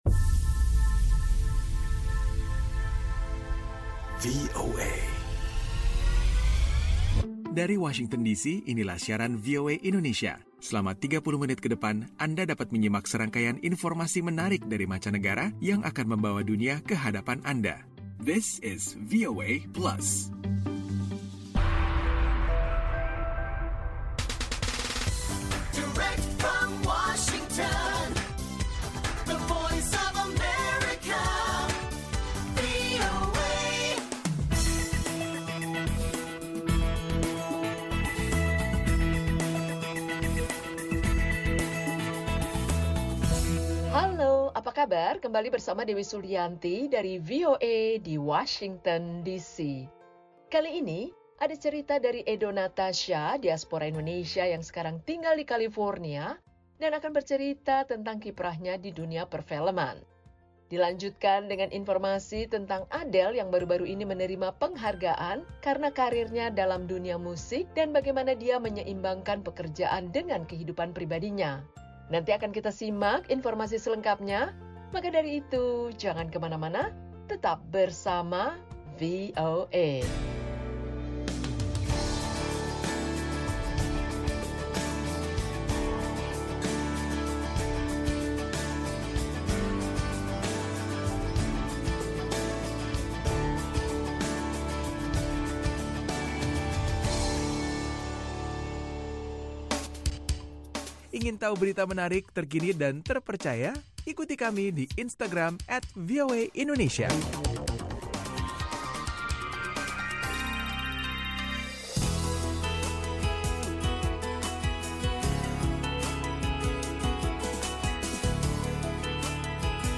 VOA Plus kali ini akan menghadirkan obrolan ringan bersama seorang diaspora Indonesia di kota Los Angeles yang seputar pengalamannya mendalami Sinematografi di Los Angeles Film School. Ada pula info tentang penghargaan yang di terima musisi Adele dari majalah The Hollywood Reporter.